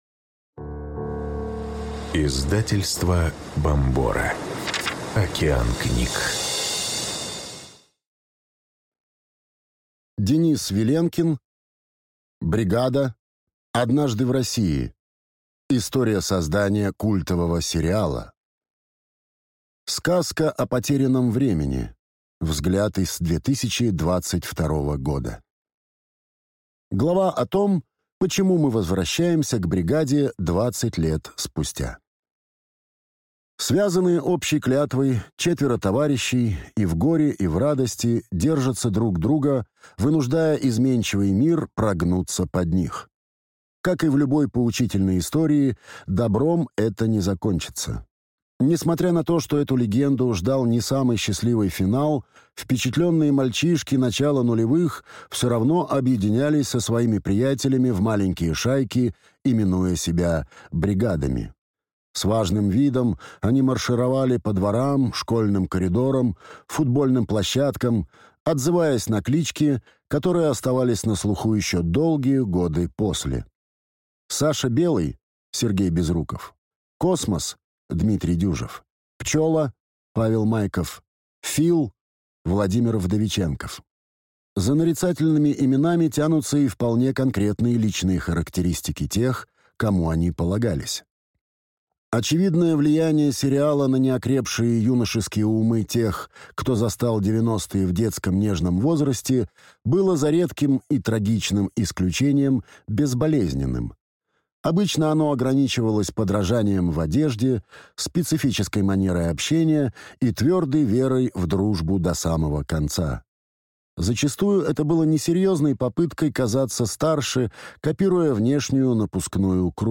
Аудиокнига Бригада. Однажды в России… История создания культового сериала | Библиотека аудиокниг